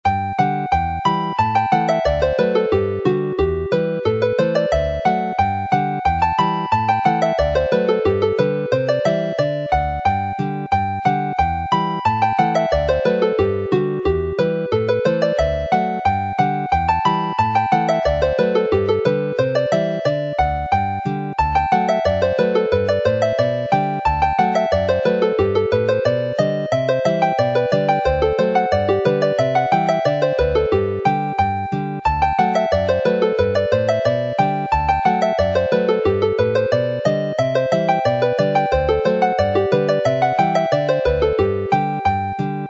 is a lively tune which needs some skill if played quickly.